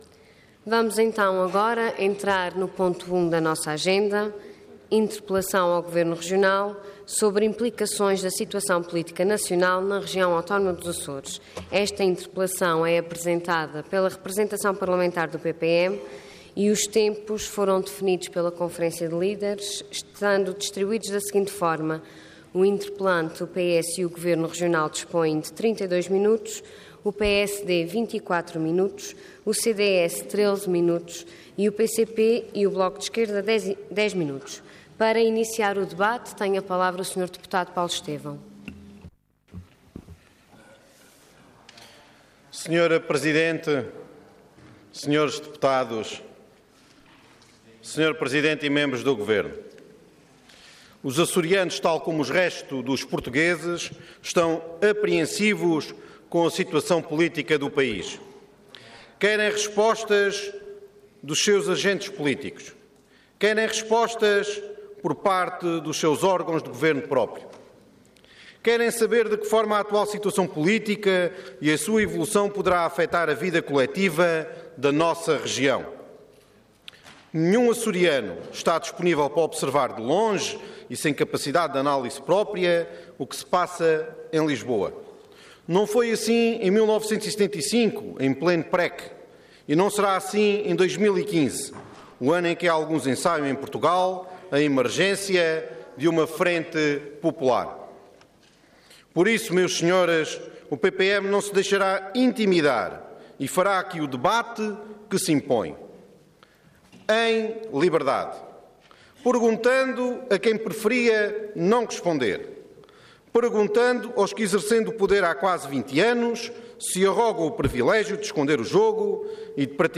Interpelação ao Governo Regional Orador Paulo Estêvão Cargo Deputado Entidade PPM